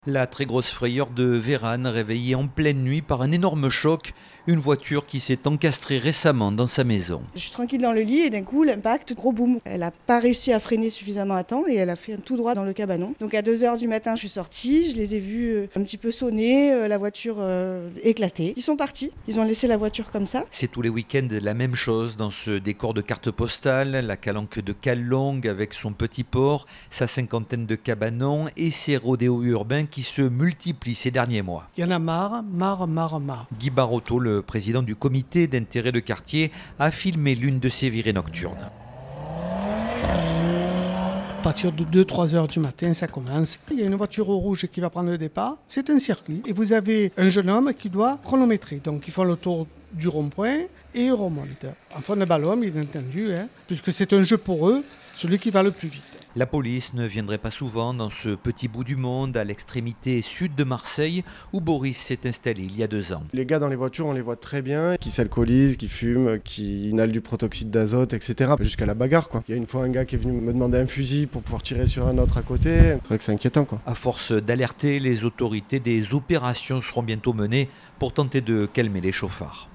Le reportage